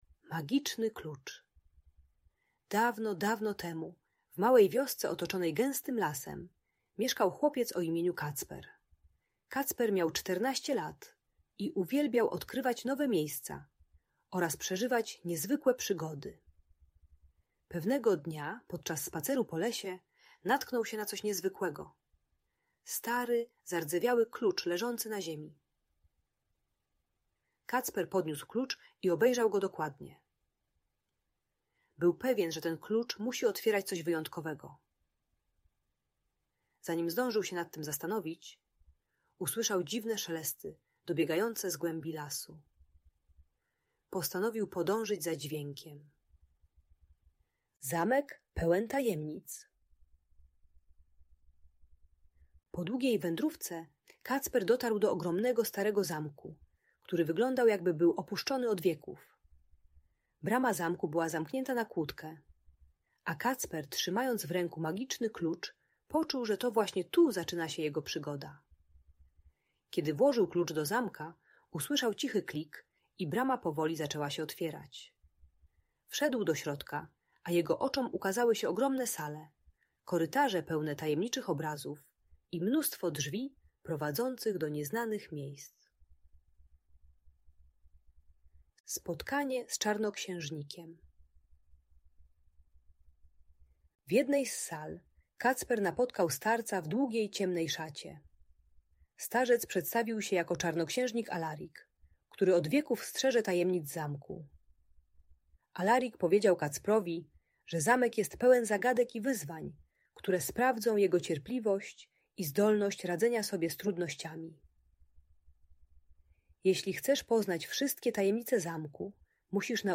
Magiczny Klucz - o Kacprze i Tajemniczym Zamku - Audiobajka